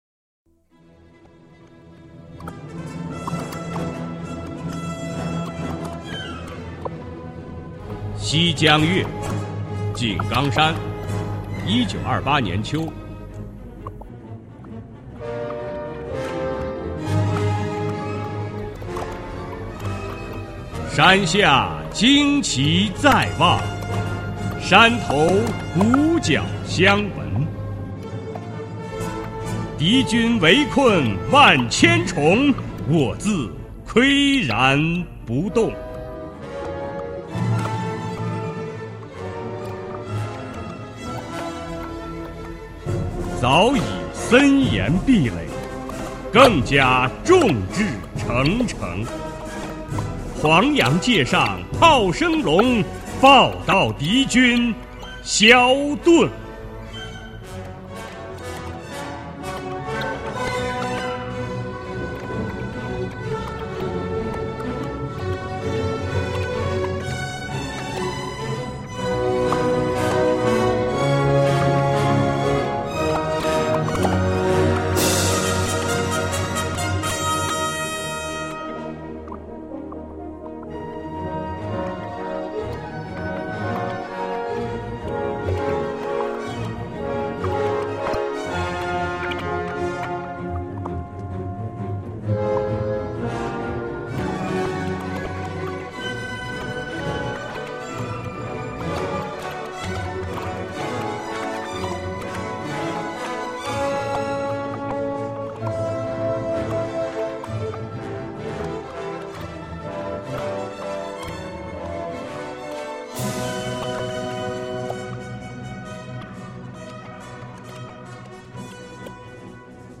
首页 视听 经典朗诵欣赏 毛泽东：崇高优美、超越奇美、豪华精美、风格绝殊